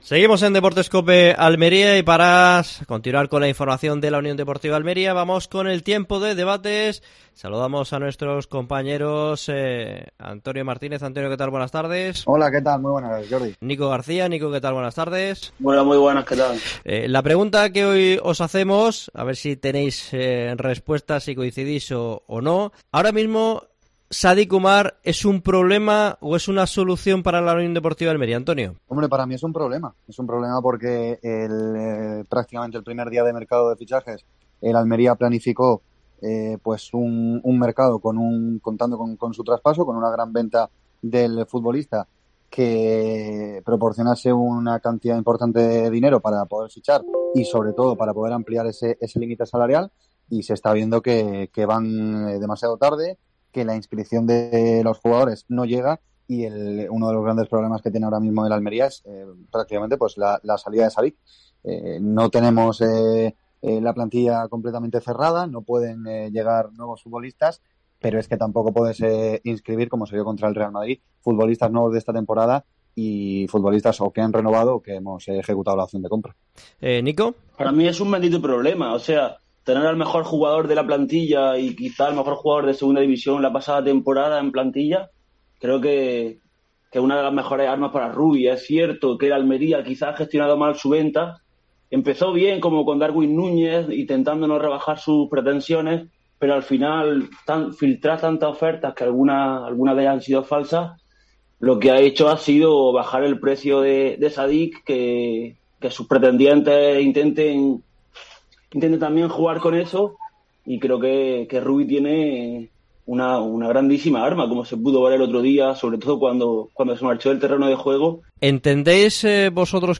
Debate Deportes COPE Almería. ¿Sadiq, solución o problema para la UDA?